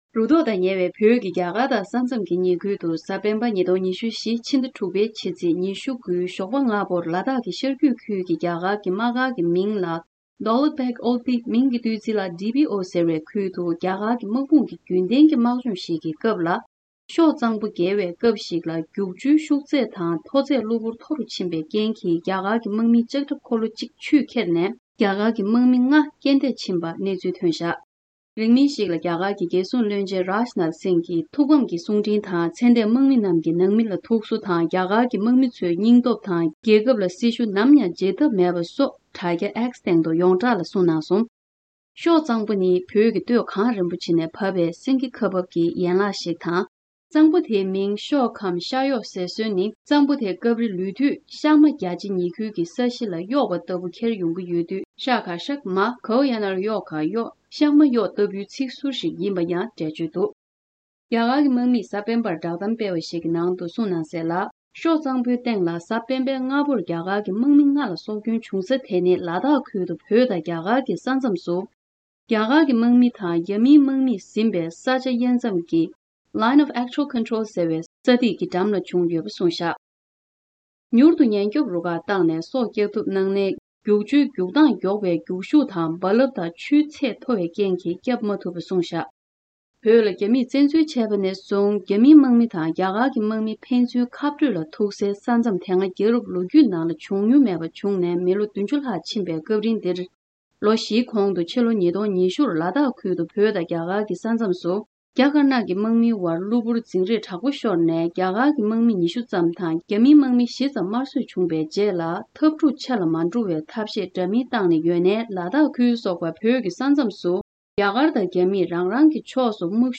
གནས་ཚུལ་སྙན་སྒྲོན་ཞུས་གནང་བ་འདིར་གསལ།